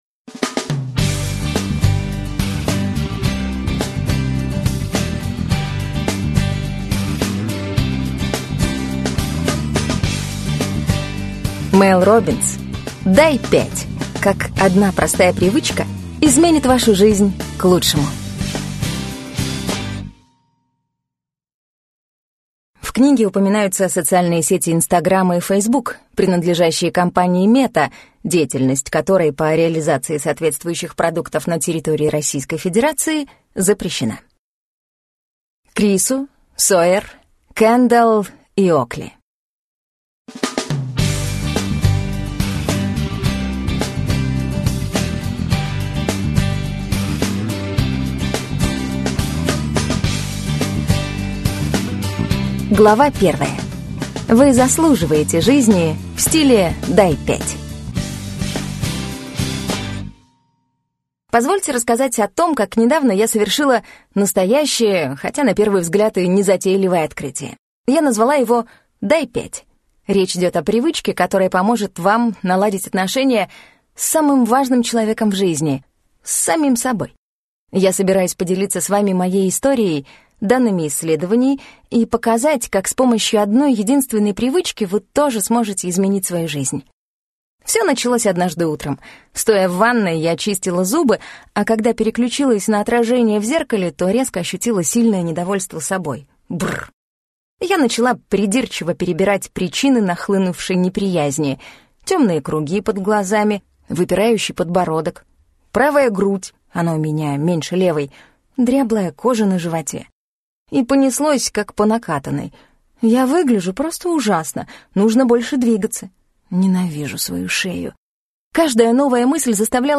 Аудиокнига Дай пять! Как одна простая привычка изменит вашу жизнь к лучшему | Библиотека аудиокниг